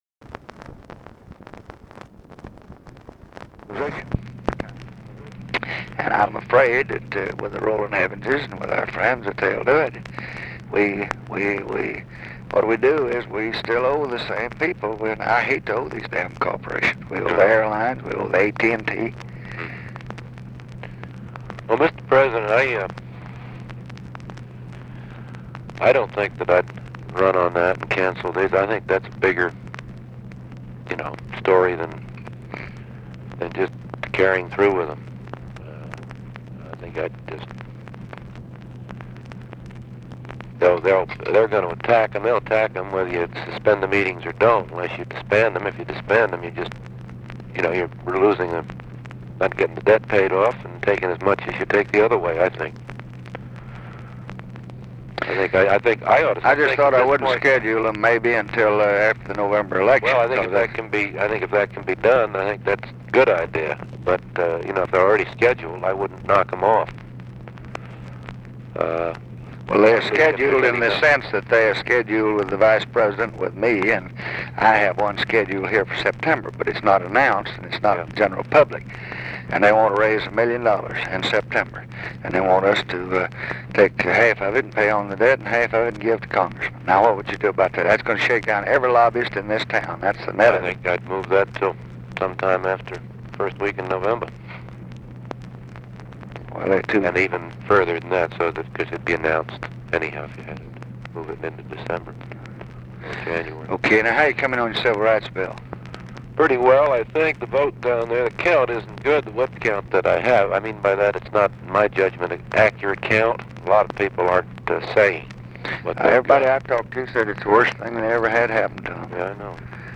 Conversation with NICHOLAS KATZENBACH and OFFICE NOISE, July 22, 1966